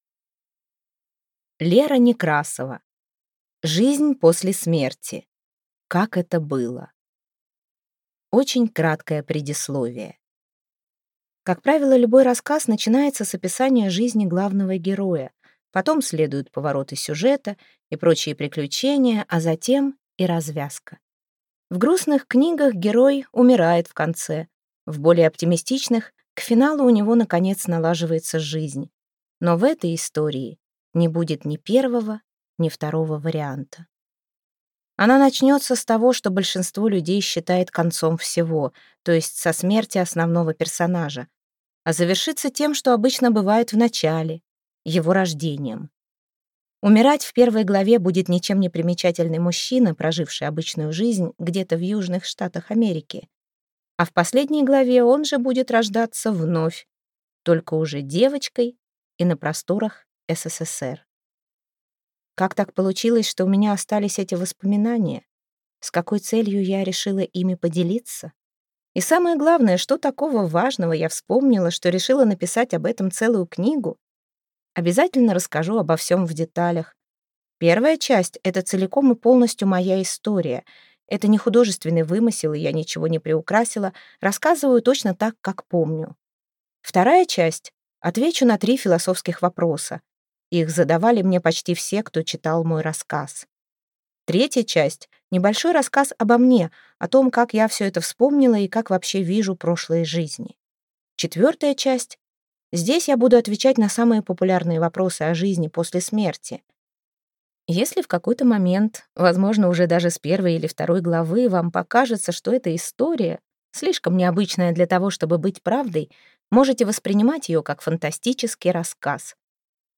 Аудиокнига Жизнь после смерти: как это было | Библиотека аудиокниг